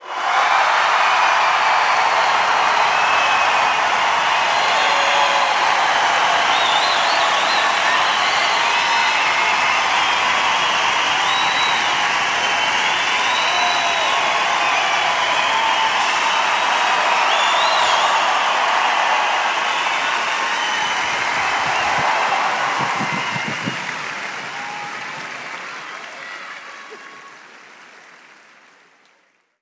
Crowd (2).wav